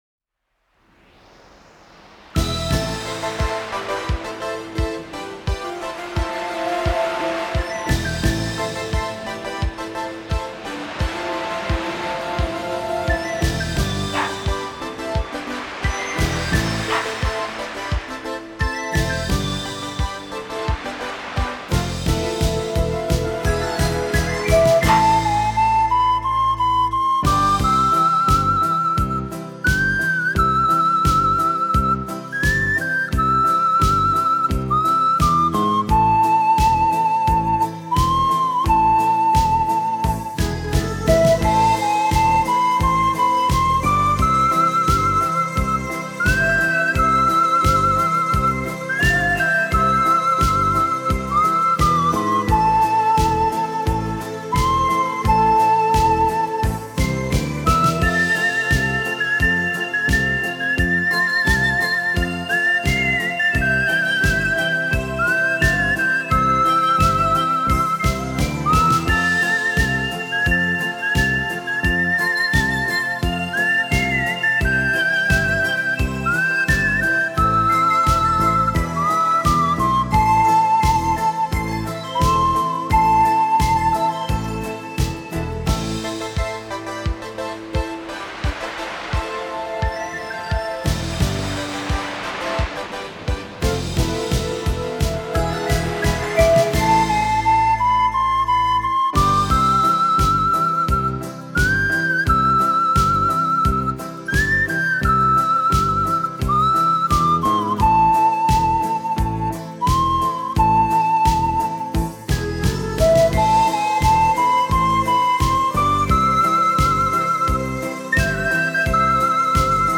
专辑风格：轻音乐
排箫，它的音色纯美自然、轻柔细腻、空灵飘逸，宛若轻风拂过。
排箫的歌声总是带着些许的感伤，好像在与过去道别，而那娓婉带着热带热情和北国幽郁的笛声，也向来被认为是最浪漫的声响。
这张专辑收录二十首排箫吹奏的金曲，就让我们随着音乐的流动，回归自然的感受，在静思冥想中，让心静下来。